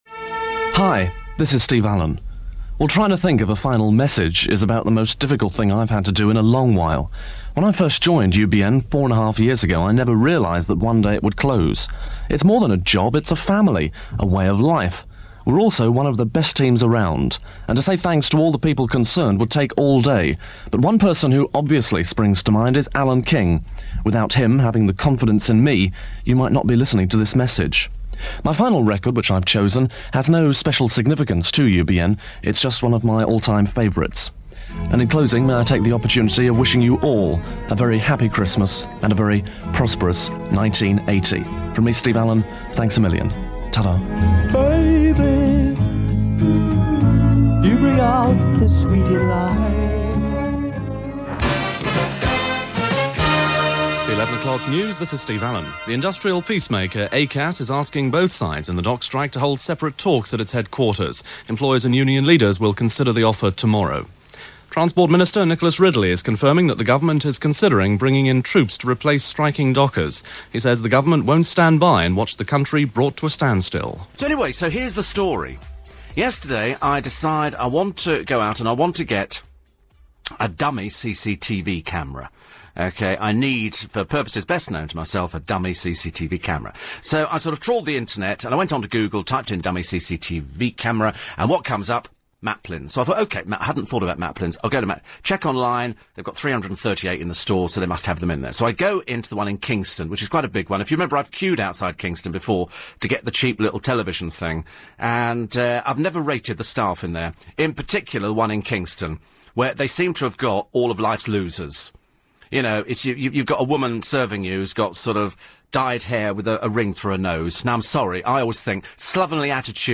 He loves chatting - and could happily continue his show for a dozen hours each day.